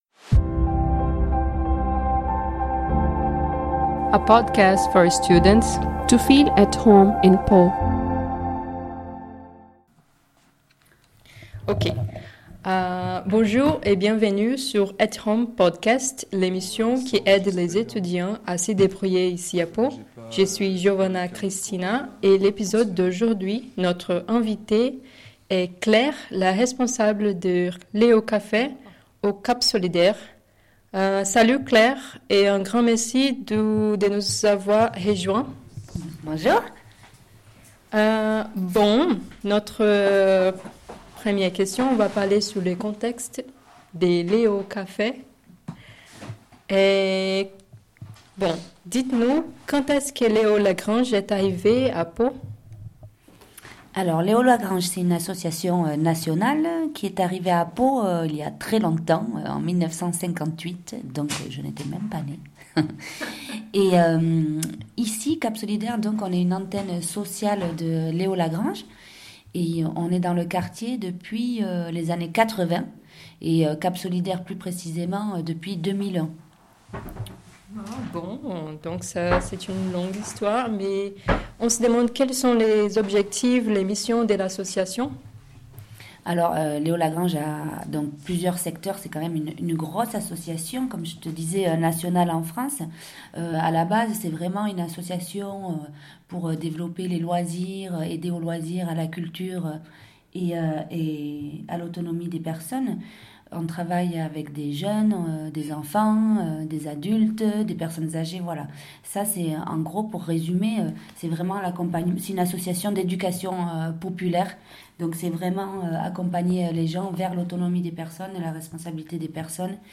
Cette première émission prend la forme d'un interview avec l'association Léo Lagrange dans laquelle les sujets suivants ont été abordés : Le contexte de l'association Le bénévolat Les projets de l'association La communauté